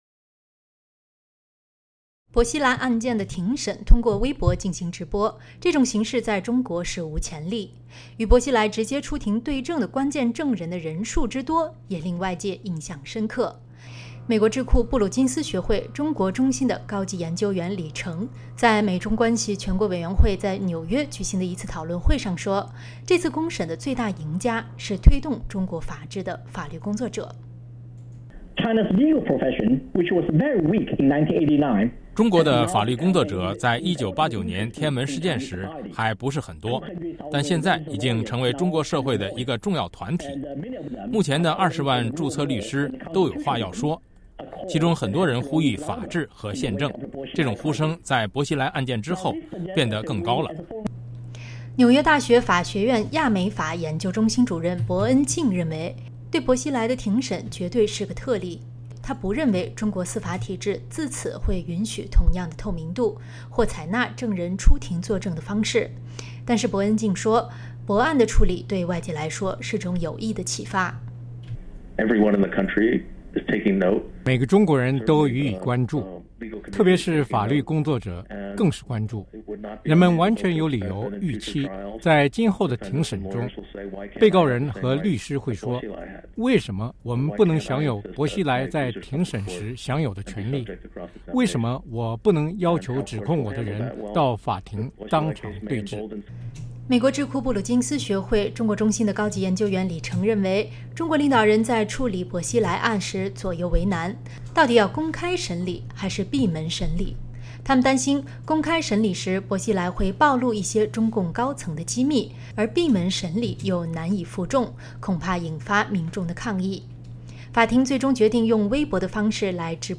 纽约报道